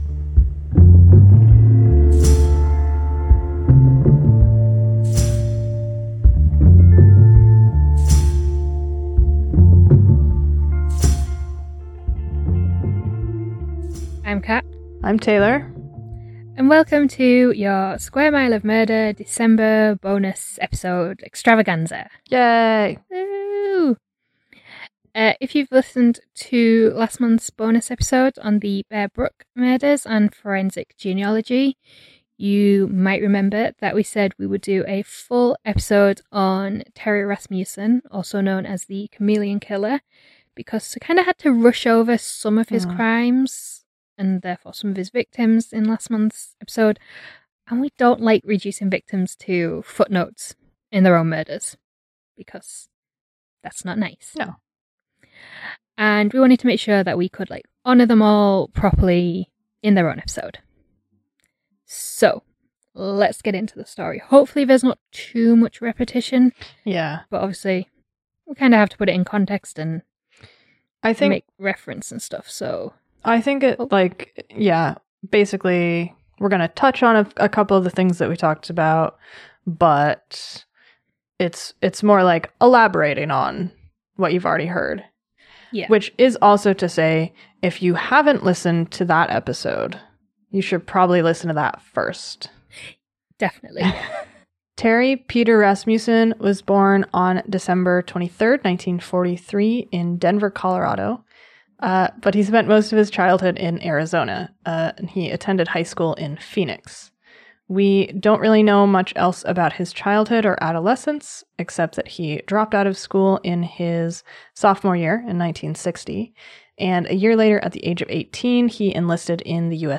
Play Rate Listened List Bookmark Get this podcast via API From The Podcast 1 Square Mile of Murder is a true crime podcast recorded and produced in Glasgow, Scotland.